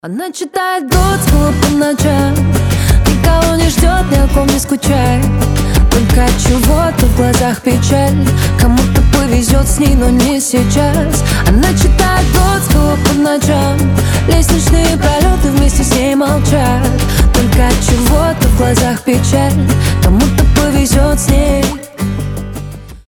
• Качество: 320, Stereo
гитара
душевные
женский голос